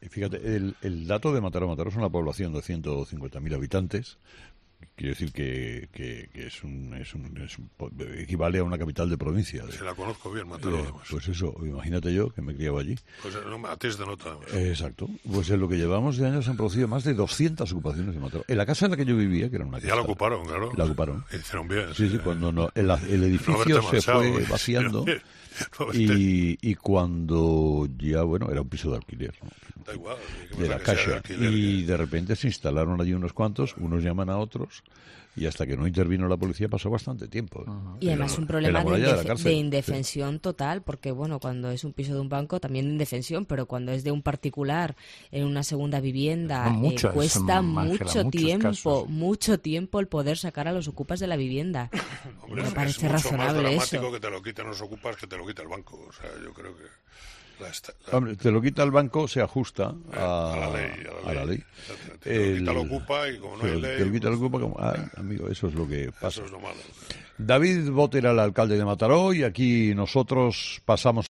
Aquí tienes el audio en el que el comunicador contaba esta anécdota en 'Herrera en COPE'.